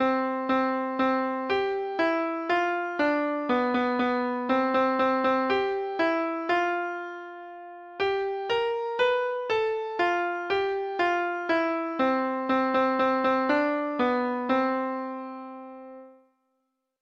Folk Songs from 'Digital Tradition' Letter T Tarry Trousers
Free Sheet music for Treble Clef Instrument